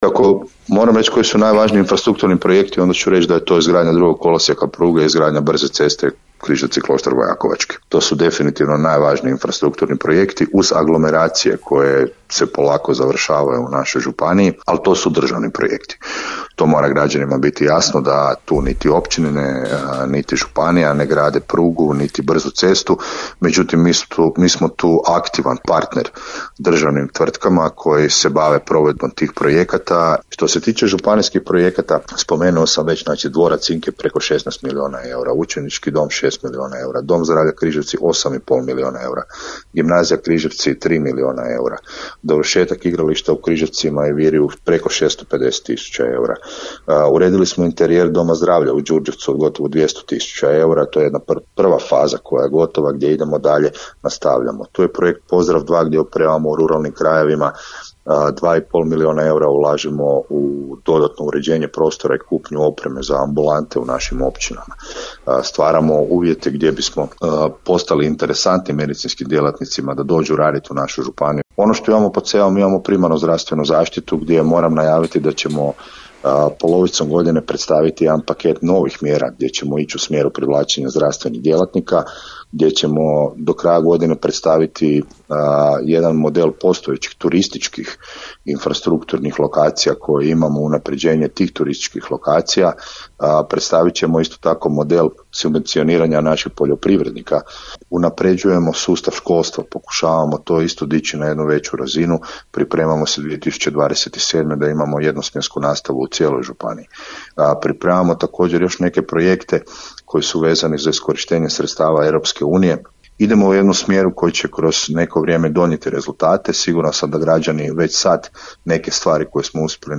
Gostujući u programu Podravskog radija, župan Tomislav Golubić govorio je o rekordnom proračunu te o aktivnostima i mjerama kojima se nastoji dodatno pomoći građanima. Osvrnuo se i na ulaganja u obrazovanje, turizam i gospodarstvo, kao i na važnost ravnomjernog razvoja županije.